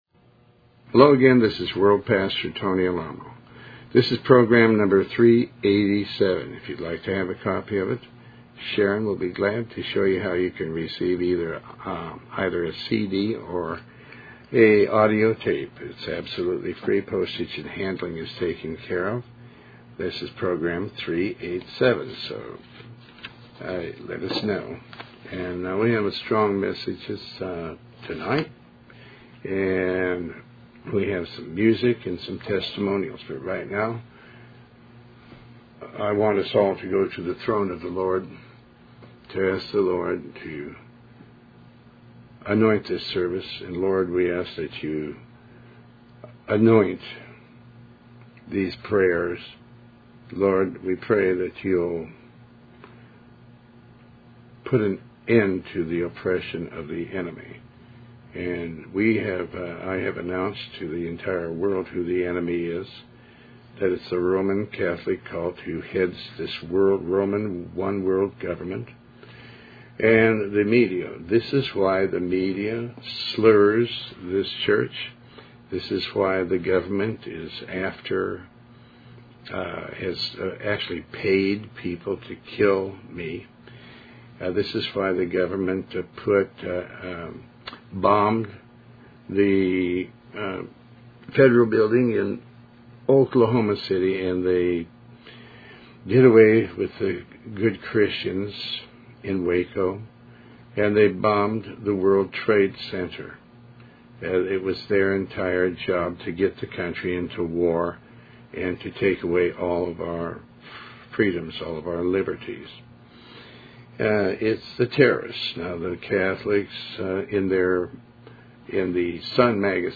Talk Show Episode, Audio Podcast, Tony Alamo and Program387 on , show guests , about Tony Alamo with Tony Alamo World Wide Ministries, categorized as Health & Lifestyle,History,Love & Relationships,Philosophy,Psychology,Christianity,Inspirational,Motivational,Society and Culture